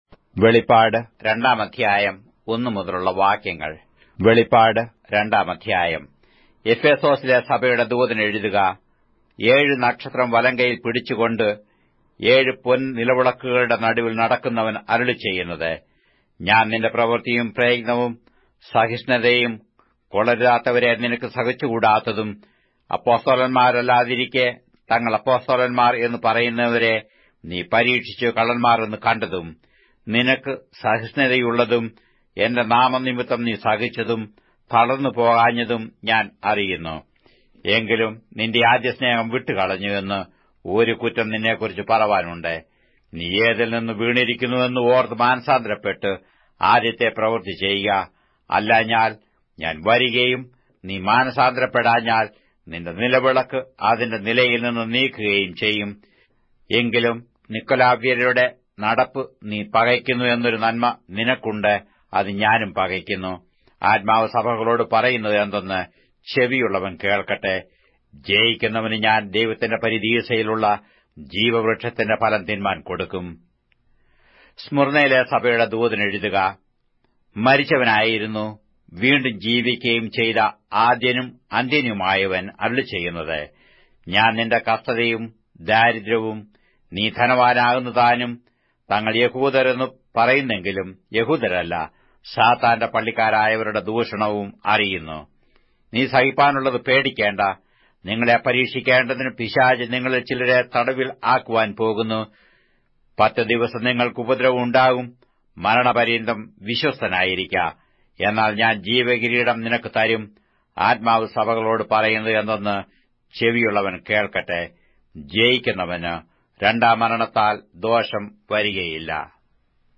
Malayalam Audio Bible - Revelation 14 in Gnterp bible version